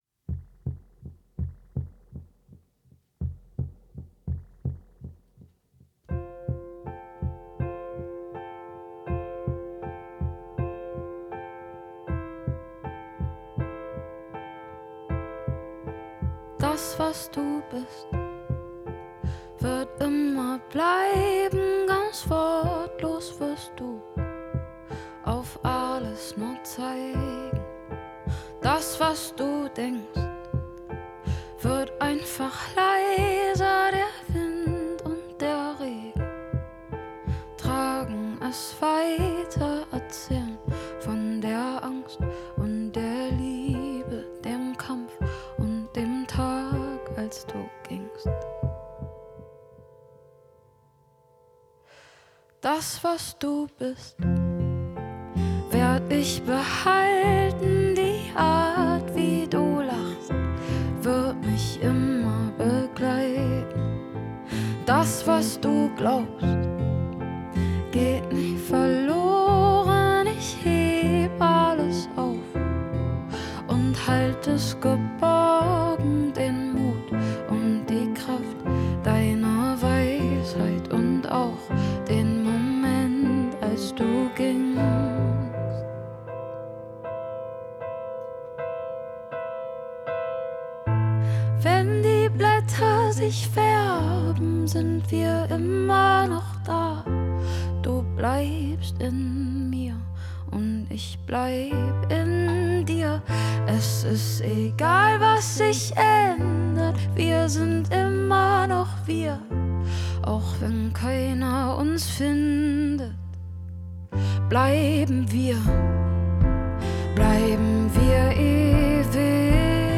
Akustik Version